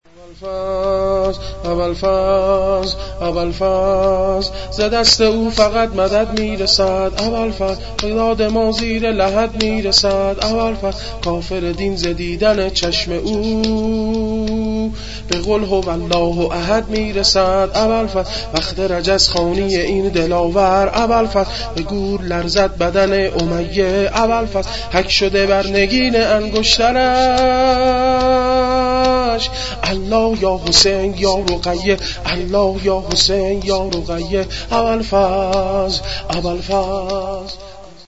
شور میلاد*ز دست او فقط مدد می رسد